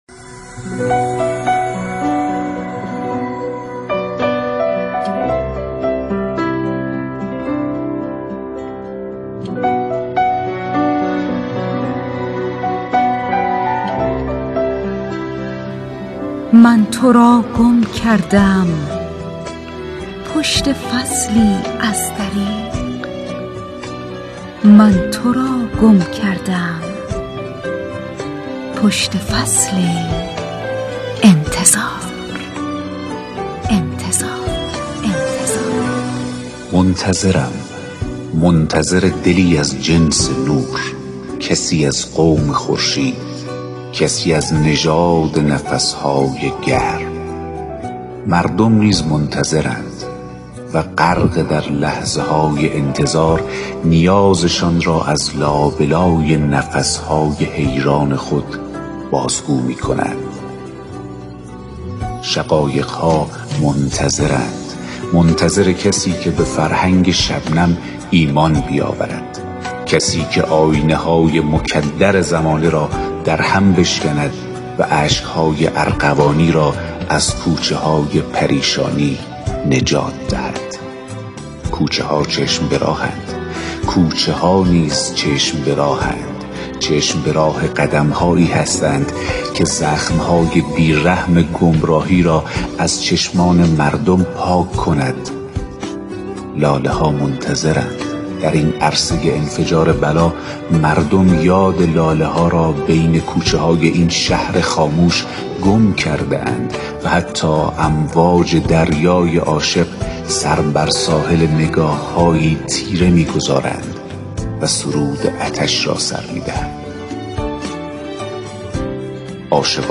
بسته خبری